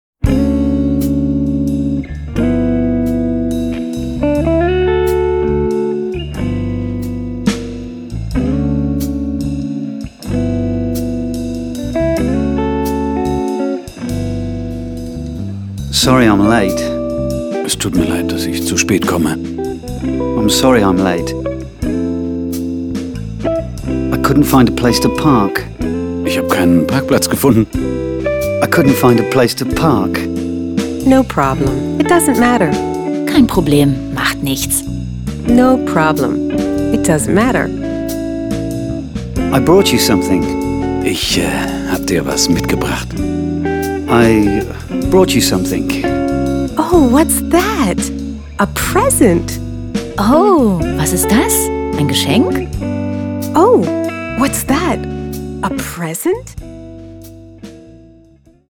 Small Talk.Coole Pop & Jazz Grooves / Audio-CD mit Booklet
Mit den supercoolen Pop & Jazz Grooves können Sie ganz entspannt und in bester Laune Englisch lernen.